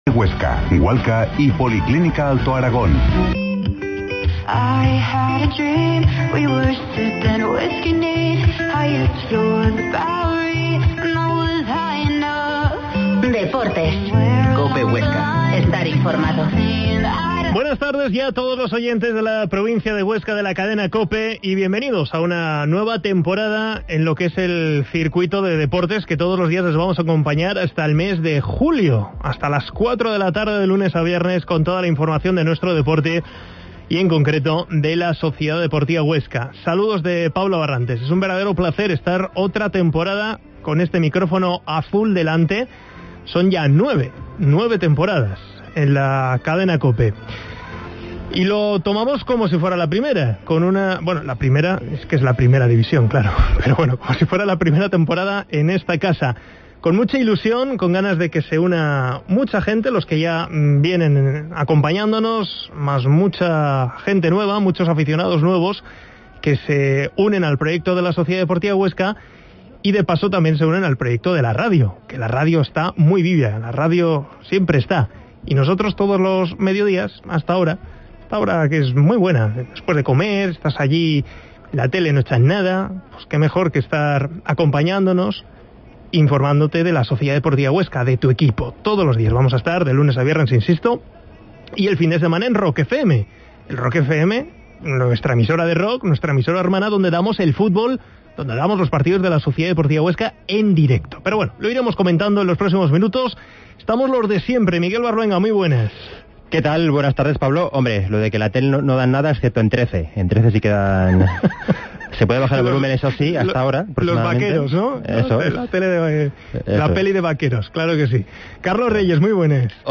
Entrevista a David Ferreiro